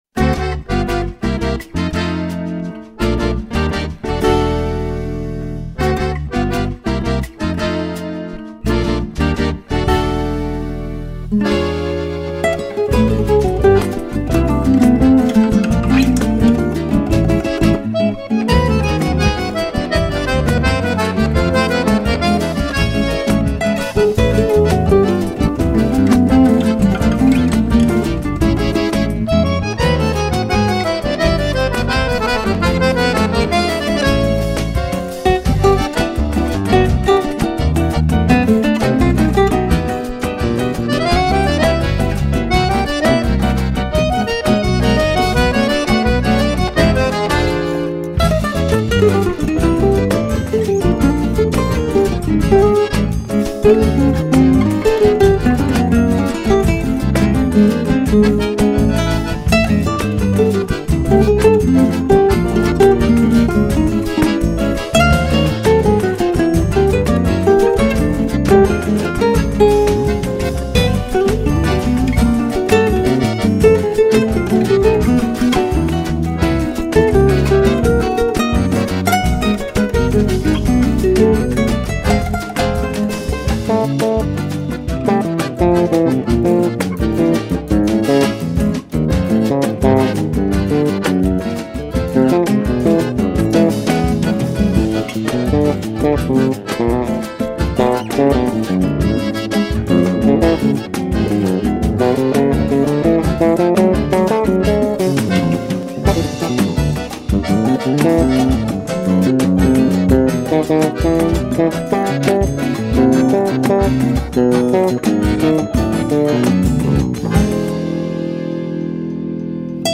1364   02:43:00   Faixa:     Jazz
Violao Acústico 6
Baixo Elétrico 6
Acoordeon
Bateria
Percussão